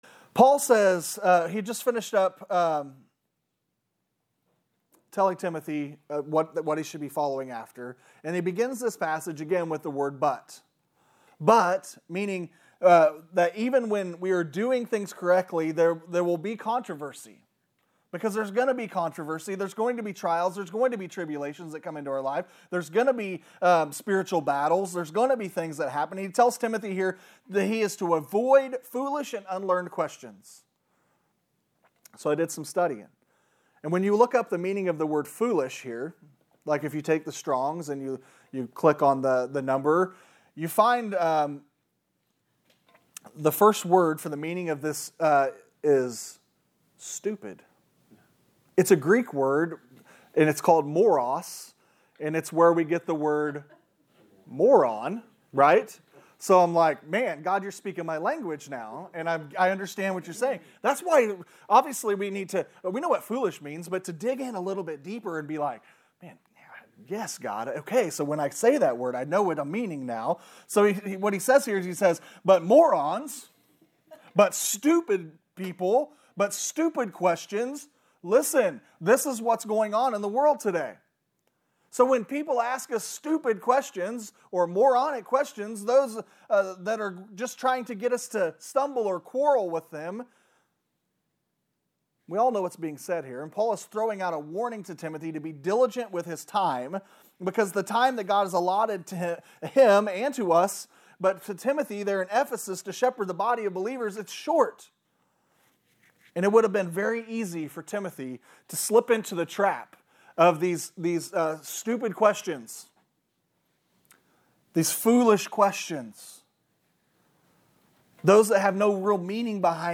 In this sermon on 2Timothy 2:23-26